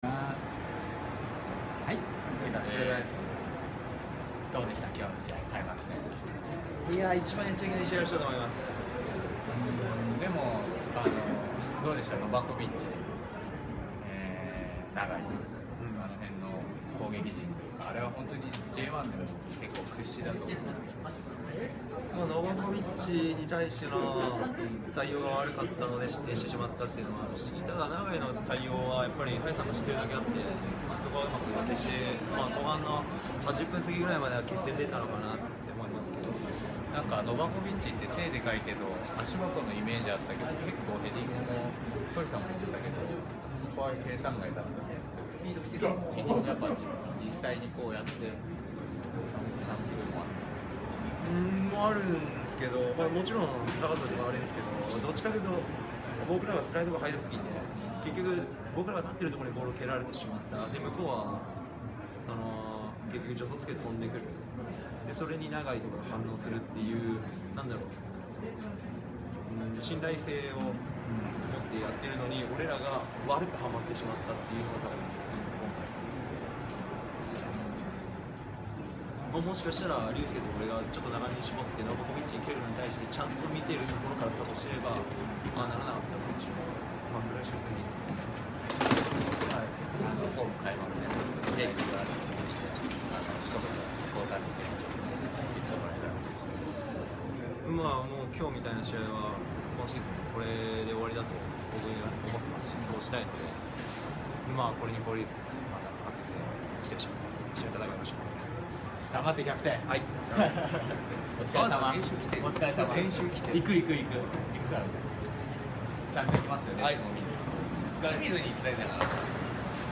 こちらのコンテンツは音声のみとなります。